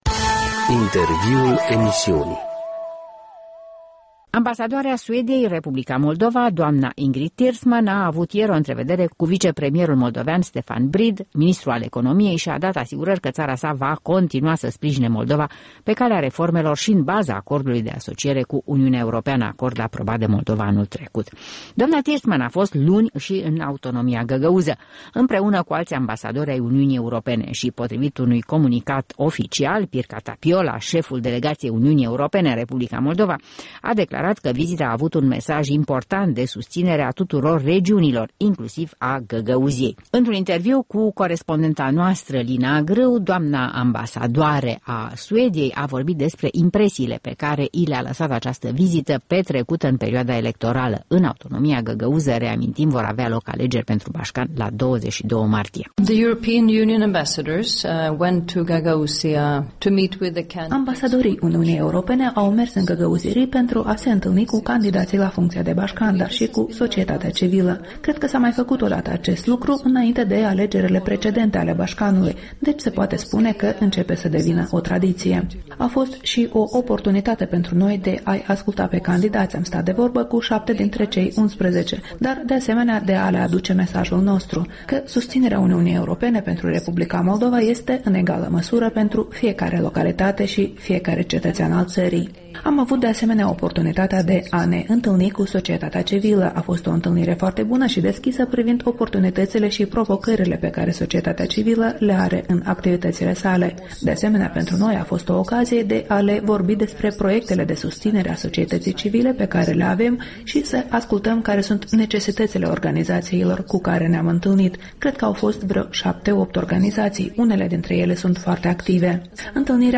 Interviurile Europei Libere: cu Ingrid Tersman, ambasadoarea Suediei în R. Moldova